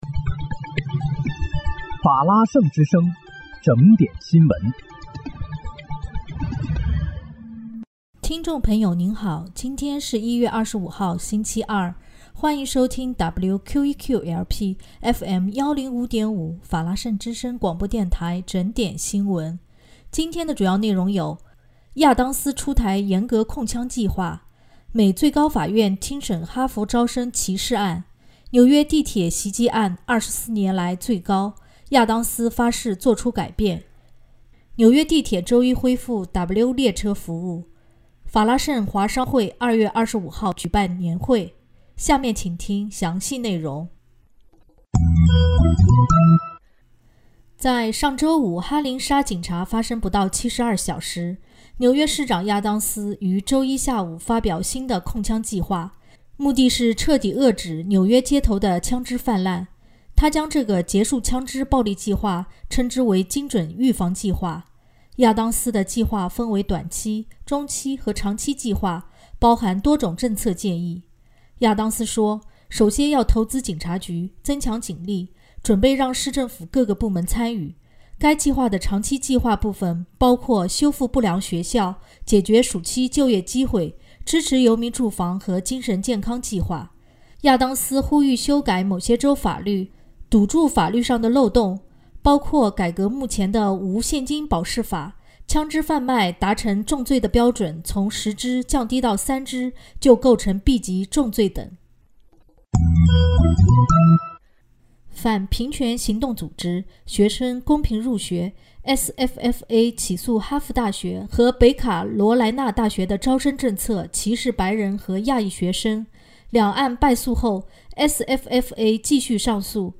1月25日（星期二）纽约整点新闻